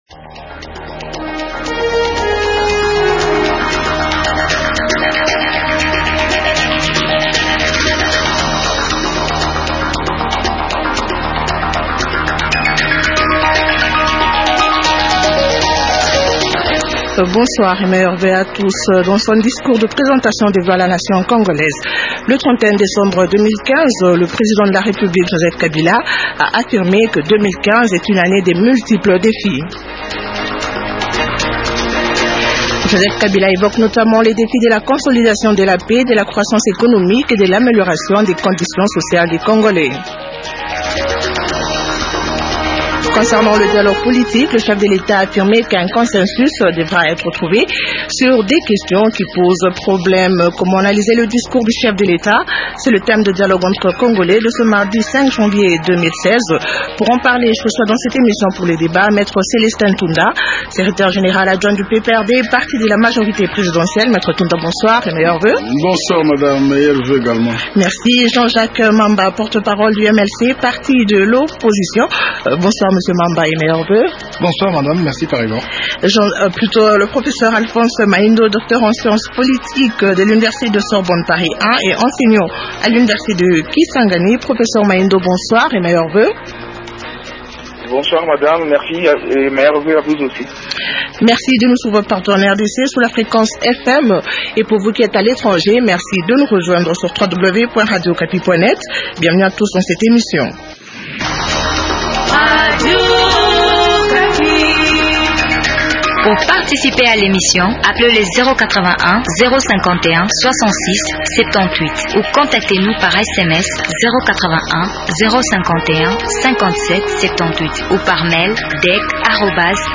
Les débatteurs de ce soir sont :